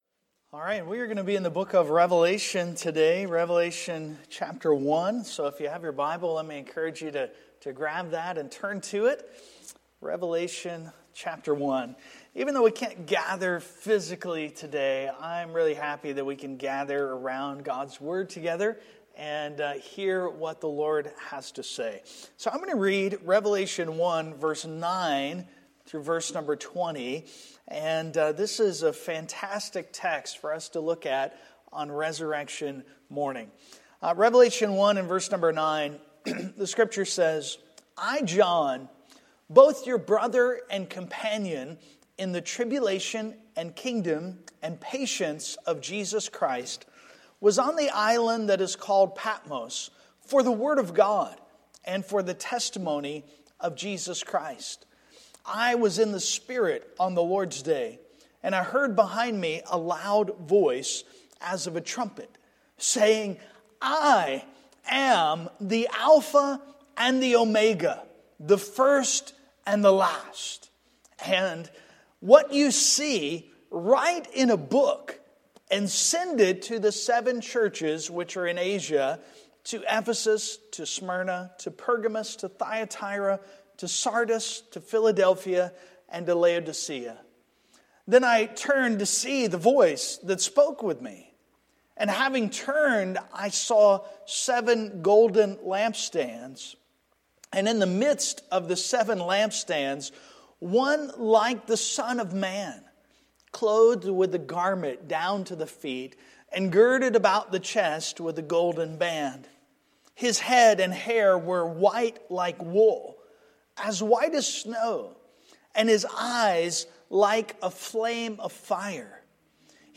Passage: Revelation 1:9-20 Service Type: Sunday Morning Download Files Notes « God’s Wisdom During Lockdown What Do We Do with the OT?